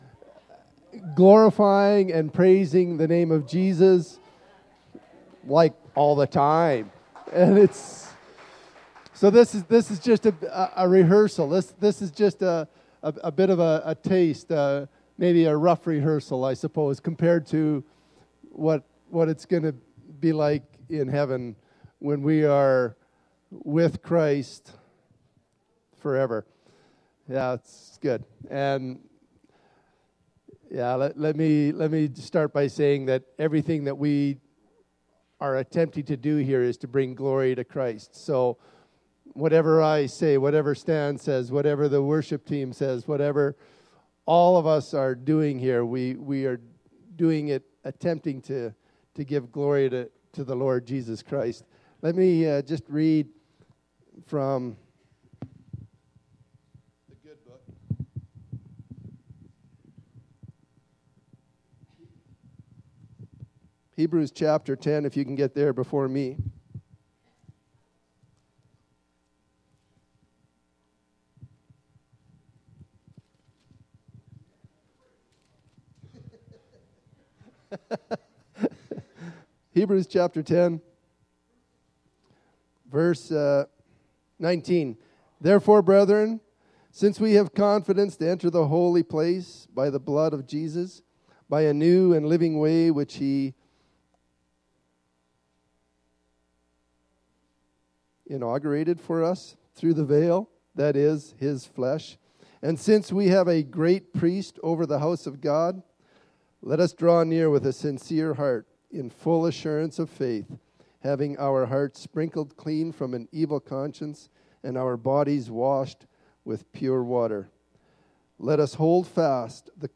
Remembrance Day Sunday Baptism, enjoy!